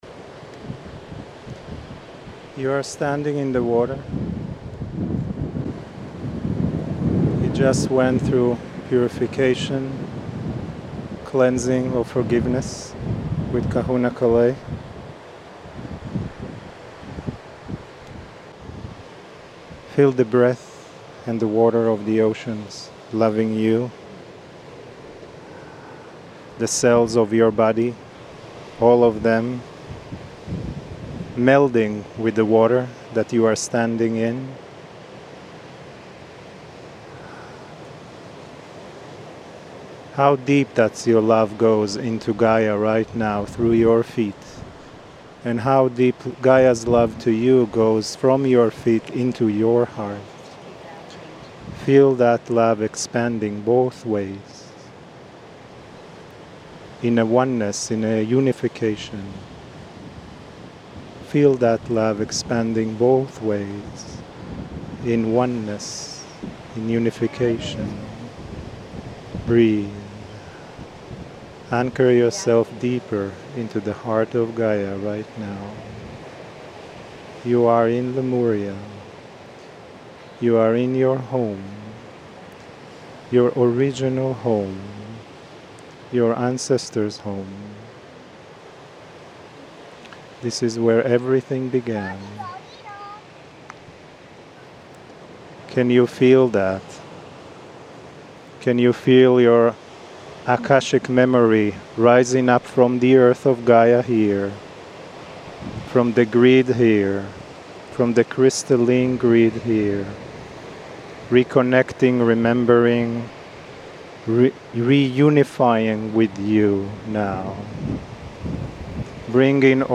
KRYON CHANNELLING